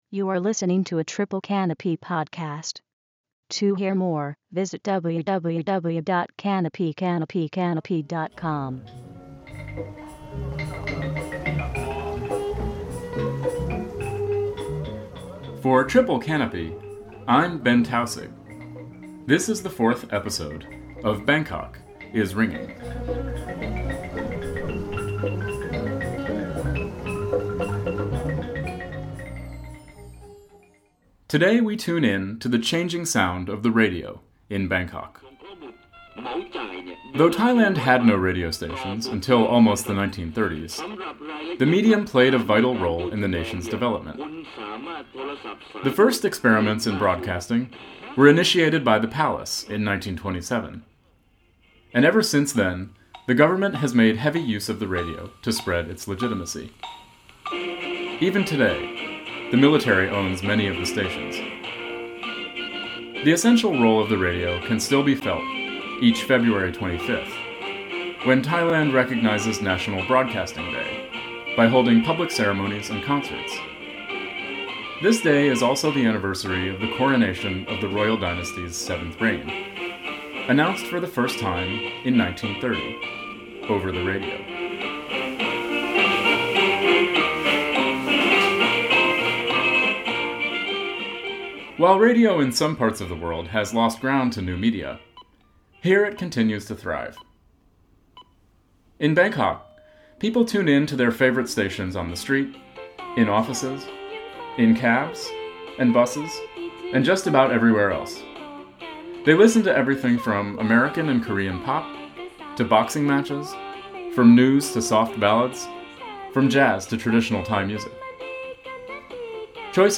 Published on February 7, 2011 Download -:-- / -:-- A series exploring the politics of urban sound in Bangkok and beyond, through first-person reporting, field recordings, and analysis.